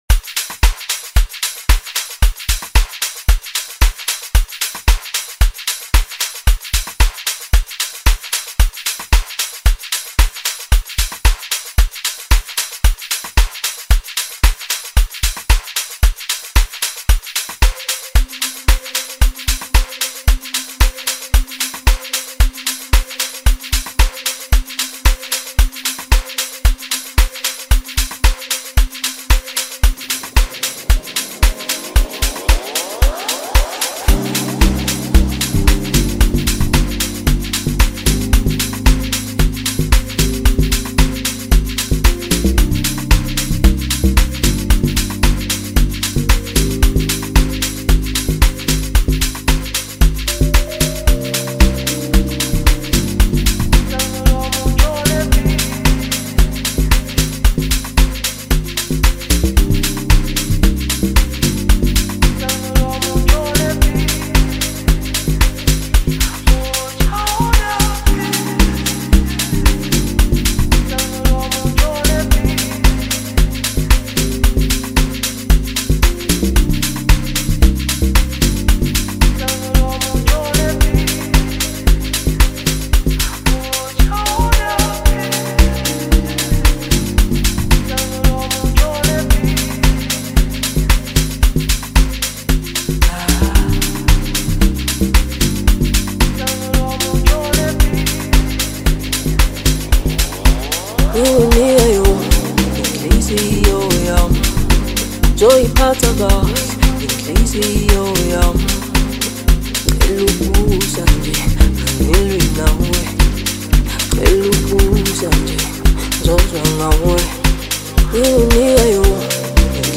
” offering smooth vocals
rich production